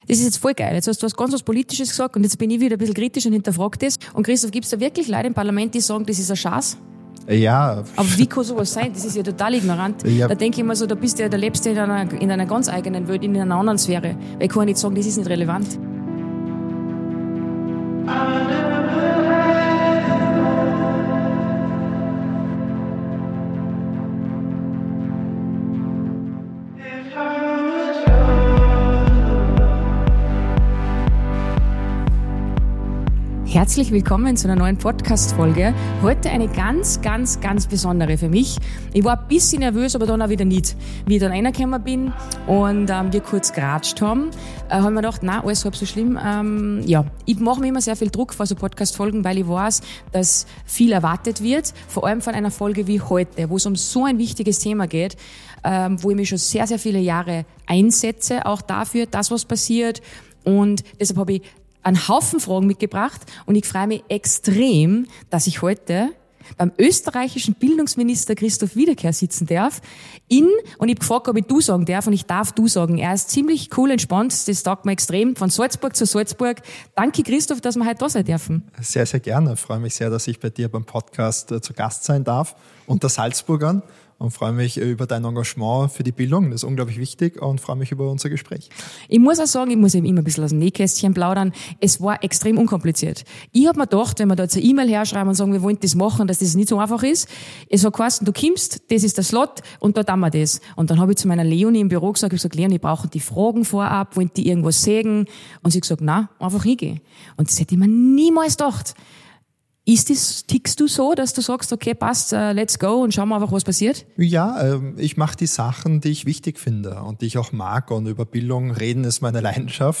Mit Christoph Wiederkehr habe ich über Social Media, Finanzbildung, Bewegung, Lehrermangel, Deutschförderung und den Druck gesprochen, den viele Kinder und Eltern gerade spüren.